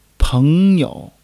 peng2--you.mp3